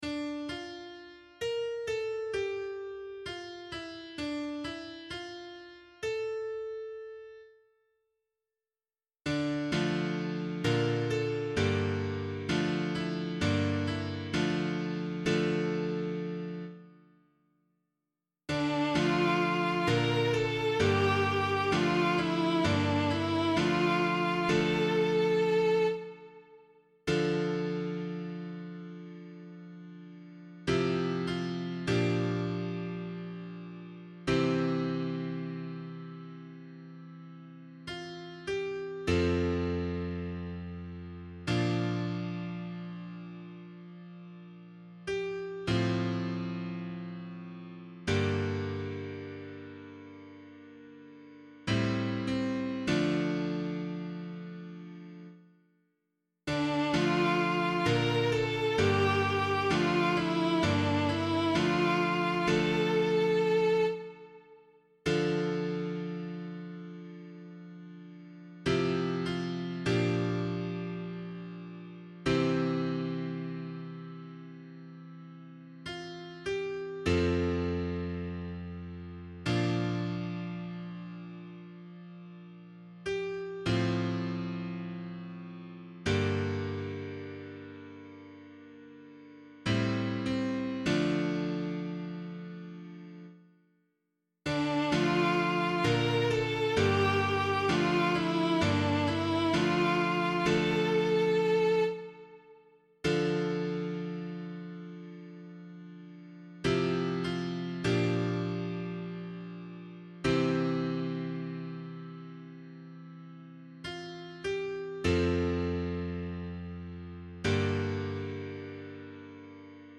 LiturgyShare Response with Meinrad Psalm Tone and Abbey Text